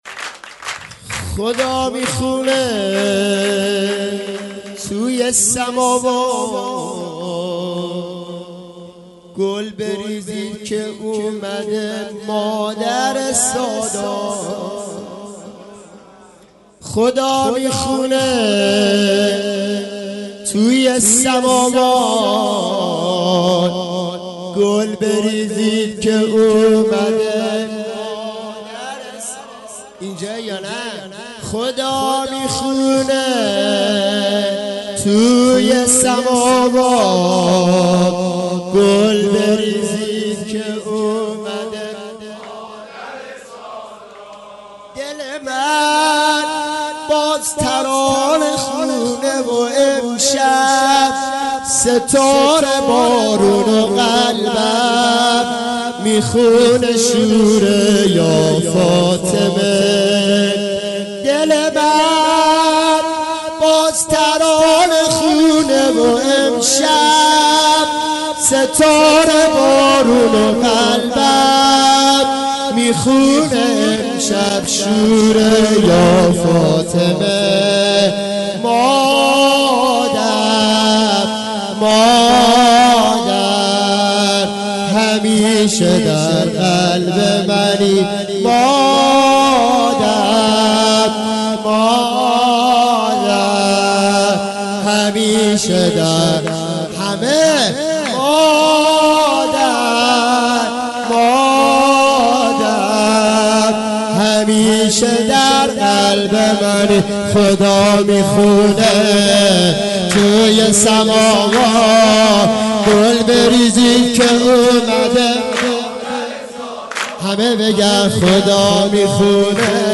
ولادت حضرت زهرا سلام الله علیها 94/1/21 :: هیئت رایة الرضا علیه السلام